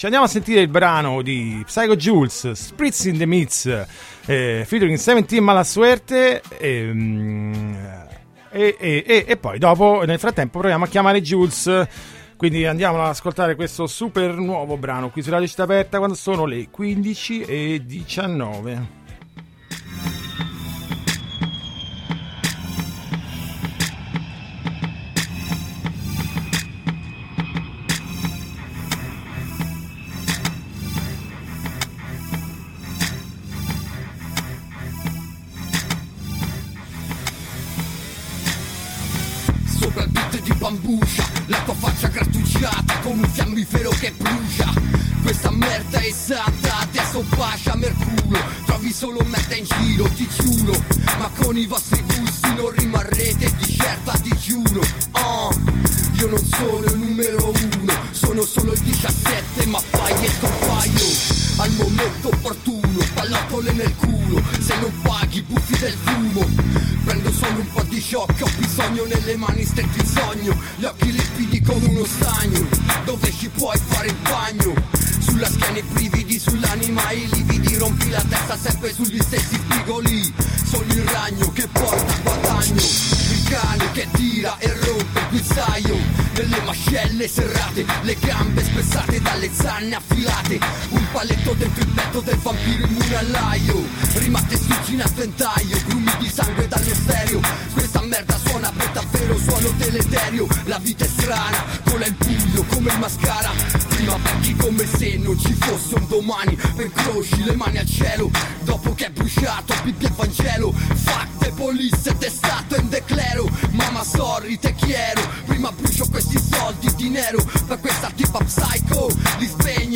Groovy Times la intervista.
Una Base HIP HOP cupa quasi horror e un cantato che spazia dallo slang romano all’inglese.
Lo abbiamo ascoltato e ne abbiamo parlato insieme a lei al telefono